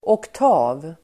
Ladda ner uttalet
Uttal: [åkt'a:v]